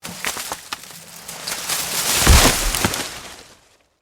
Tree Fall 2
Tree Fall 2 is a free sfx sound effect available for download in MP3 format.
Tree Fall 2.mp3